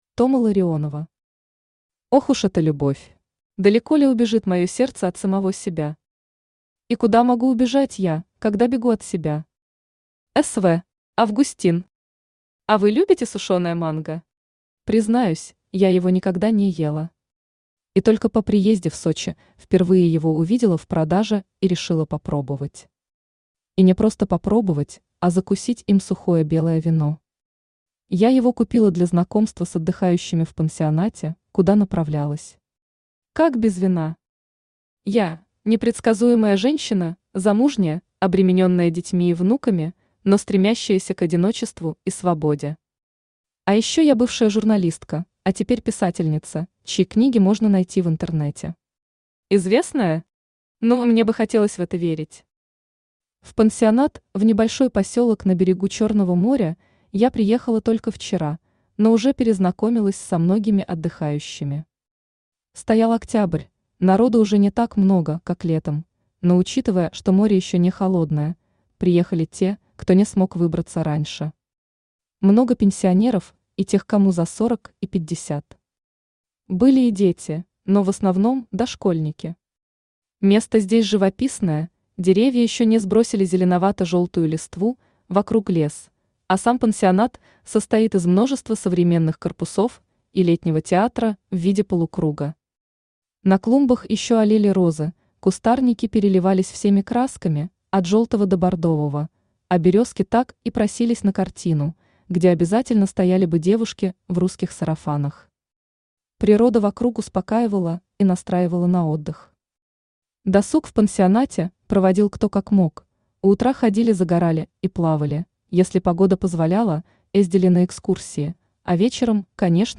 Аудиокнига Ох уж эта любовь | Библиотека аудиокниг
Aудиокнига Ох уж эта любовь Автор Тома Ларионова Читает аудиокнигу Авточтец ЛитРес.